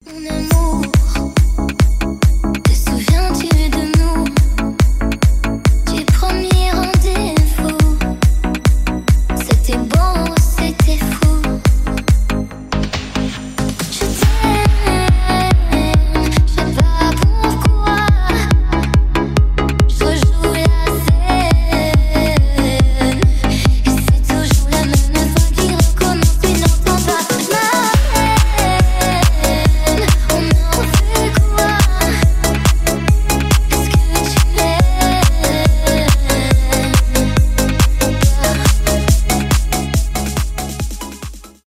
deep house , танцевальные
ремиксы